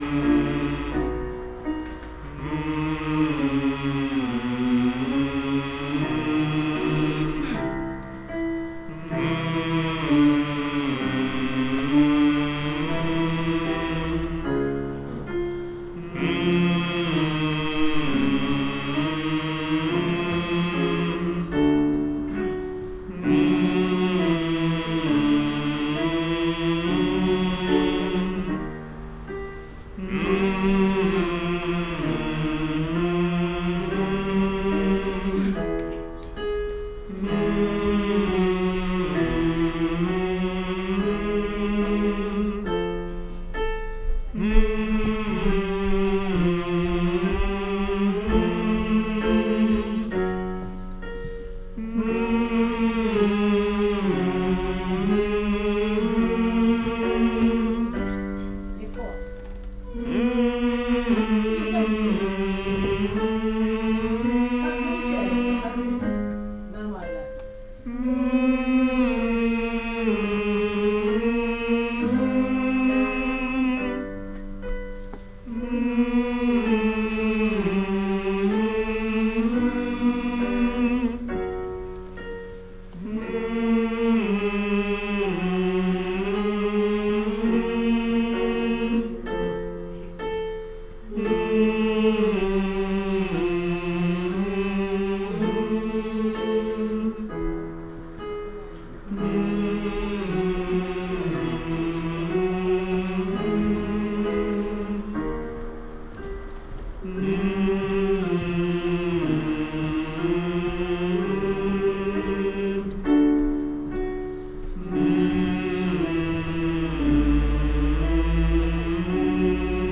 Сравнение звучания микрофона Studio Project 1 и переделанного Invotone. Распевка хора. Запись 24 бит 44,1 кгц на Tascam DR-40. Применена только нормализация дорожек в Cubase 10 Pro.